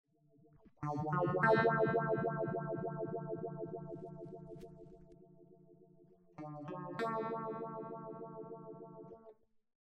Enregistrer directement dans une table yamaha MG-12/4, avec une carte ECHO MIA MIDI.
Le son est neutre,sans traitement.
DEPTH POTS MAX -> MIN :
smallstone -COLOR ON-DETPH-MAX au MIN.mp3